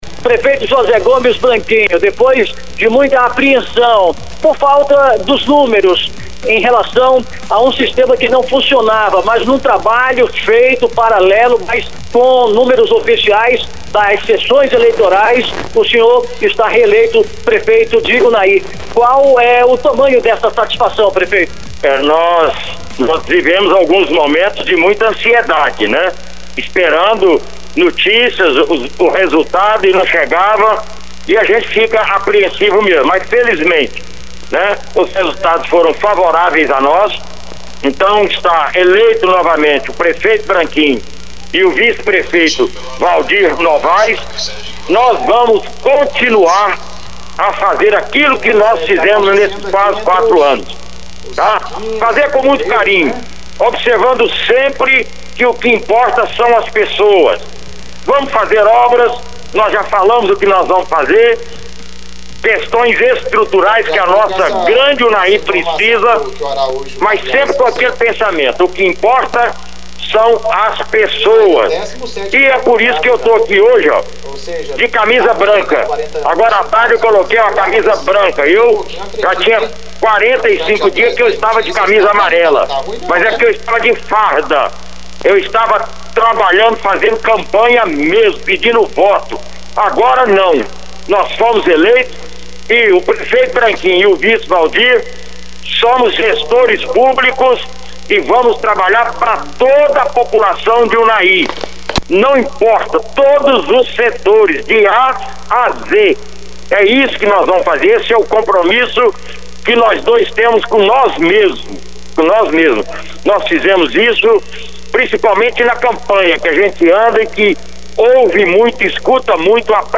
Ainda durante a noite, a reportagem Veredas ouviu também o candidato Branquinho e o seu vice, Waldir Novais, que falaram na condição de reeleitos.